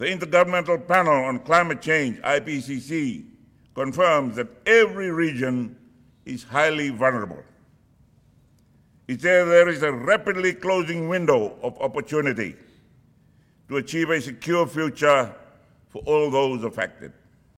He delivered this message during the commencement of the Pacific Small Islands Developing States High-Level Dialogue on Climate Change in Suva.